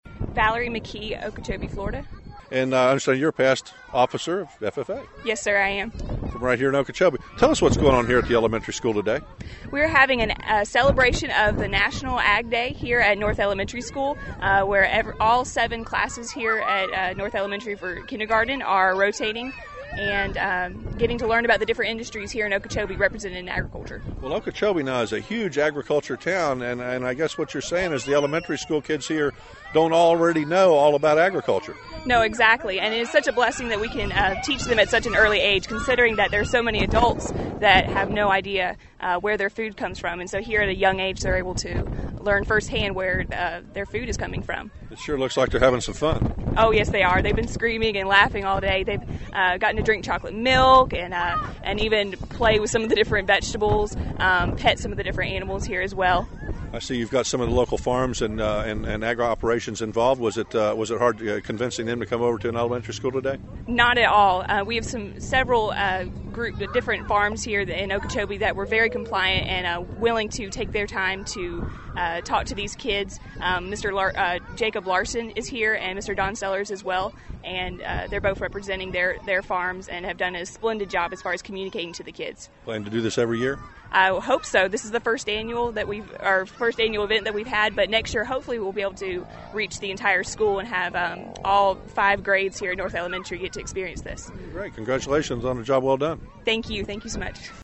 Listen to these interviews as local kindergarten teacher ...